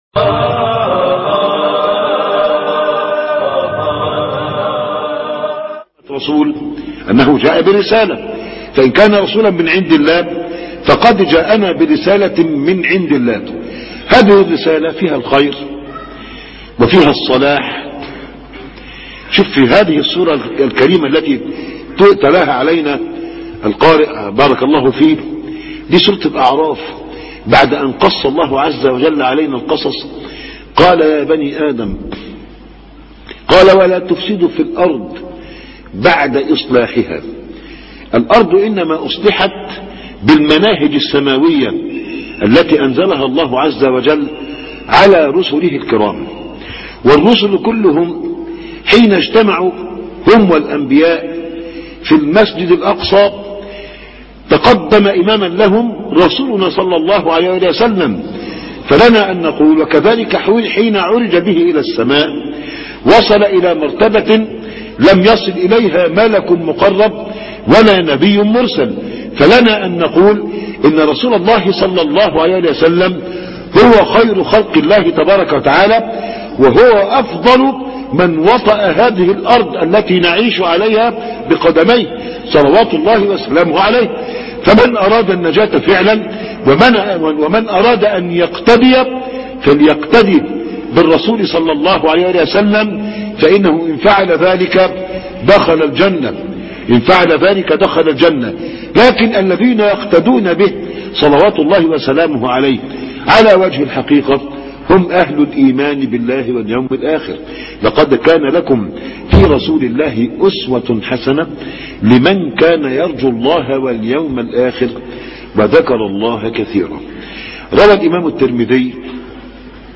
محاضرة من مسجد السنية بمصر القديمة(8-1-2012)اعادة نظر